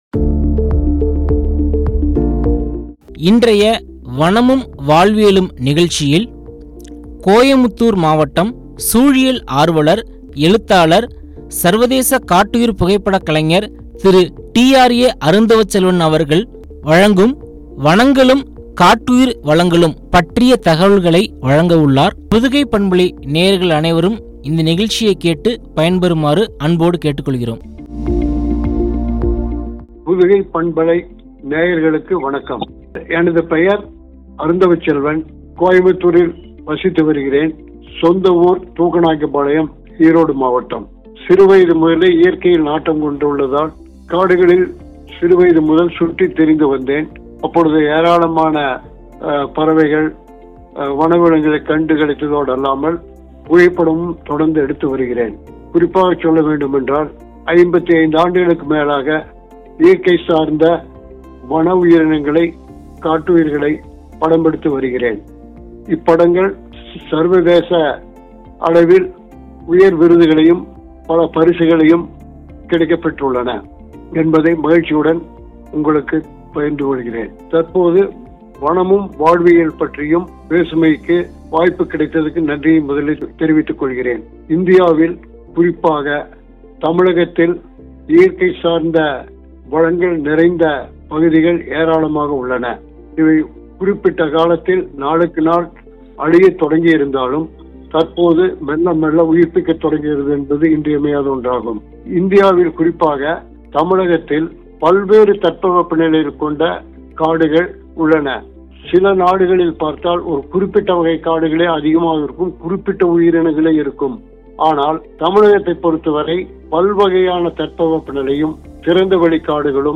காட்டுயிர் வளங்களும்” குறித்து வழங்கிய உரையாடல்.